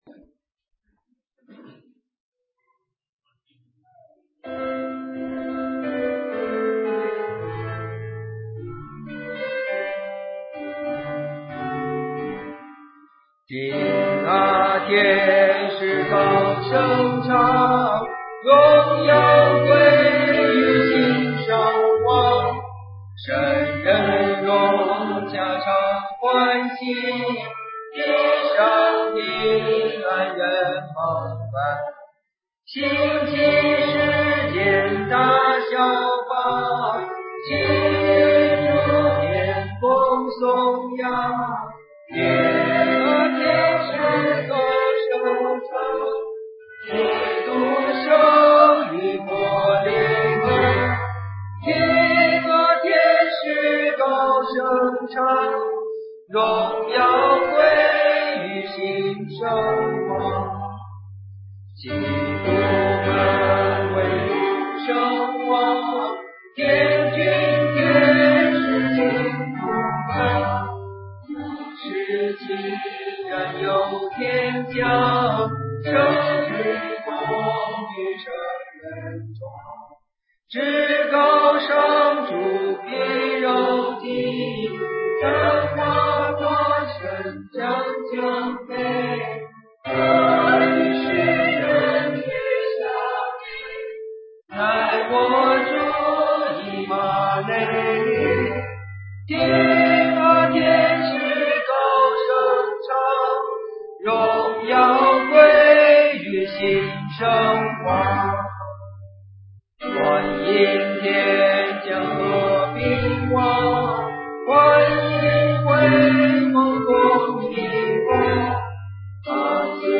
101224: 2010年圣诞音乐布道会